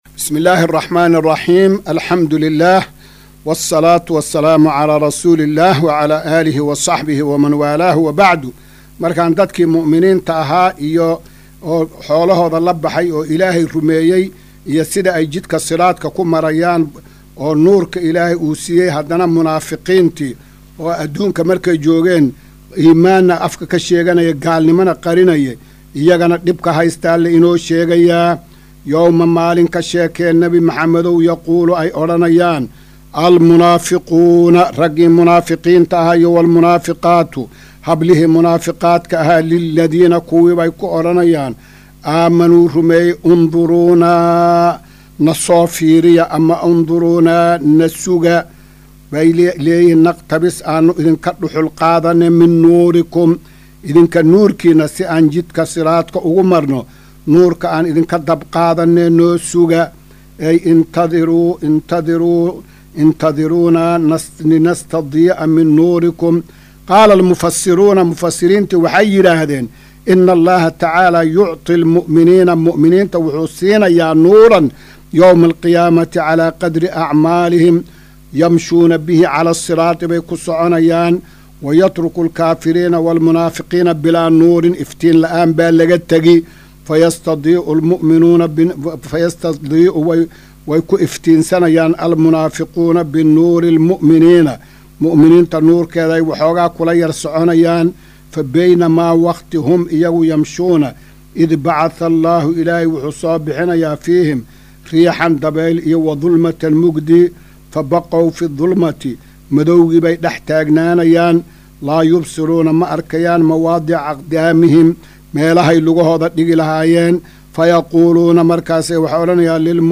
Maqal:- Casharka Tafsiirka Qur’aanka Idaacadda Himilo “Darsiga 257aad”